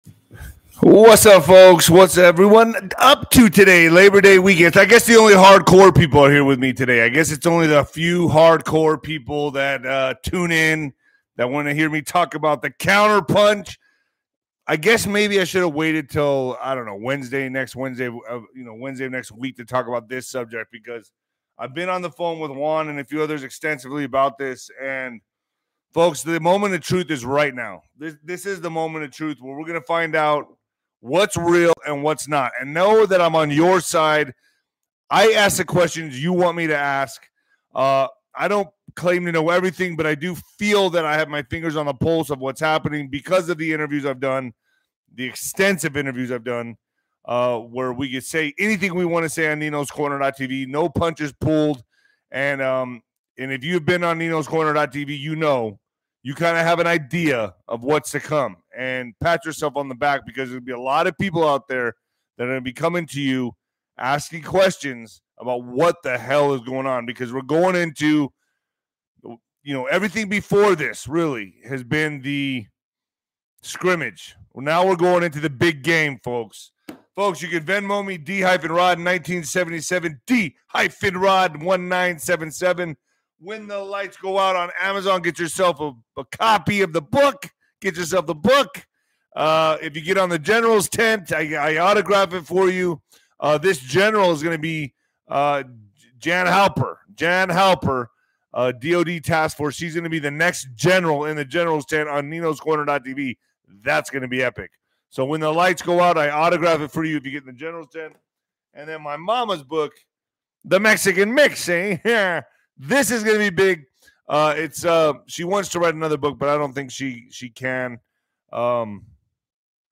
The monologue covers various topics, including an upcoming counterpunch, a weight loss supplement, and various guests he will be hosting on his show.